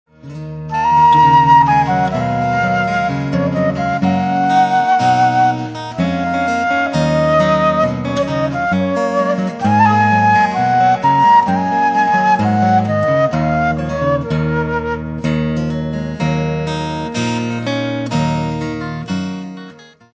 chitarra acustica